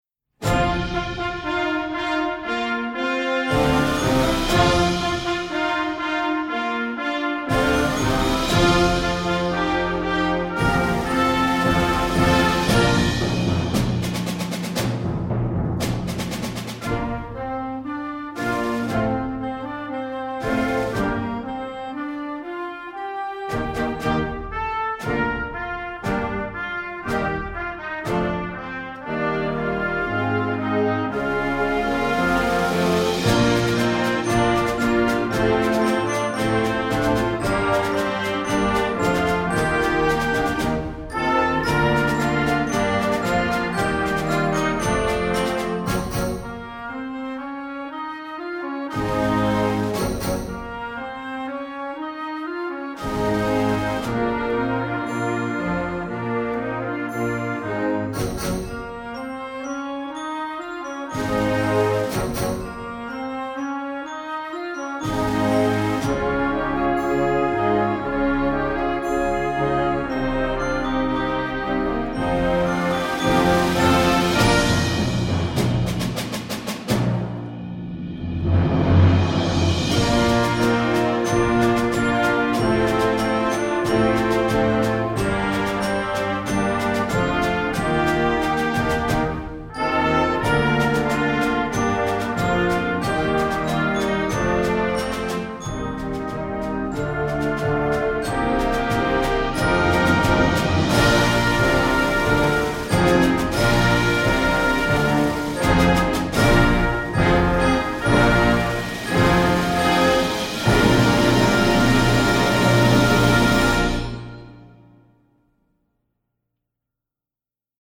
Gattung: A Celebration for Band
Besetzung: Blasorchester
Beginning bands will sound magnificent!